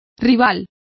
Complete with pronunciation of the translation of rival.